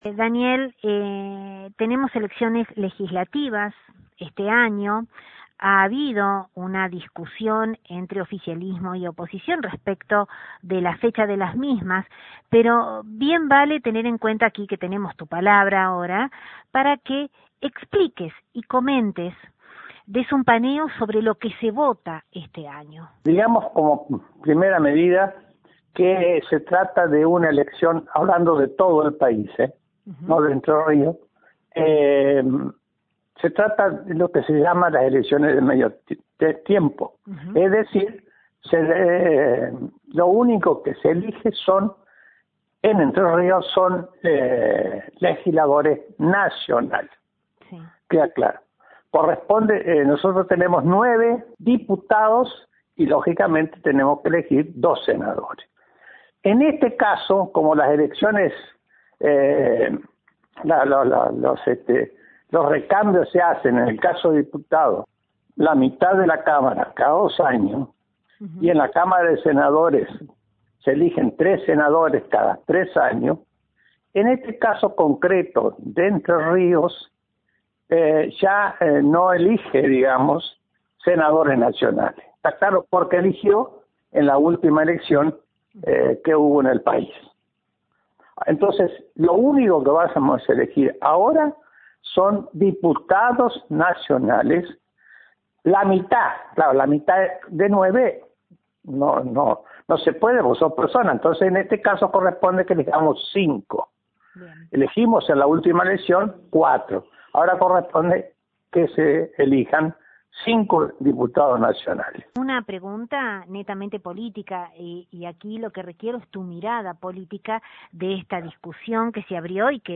Desde LT39 NOTICIAS, dialogamos con el Doctor Daniel Sobrero, ex legislador provincial, mandato cumplido y prestigioso integrante del foro local; amén de ser un fiel representante del centenario partido.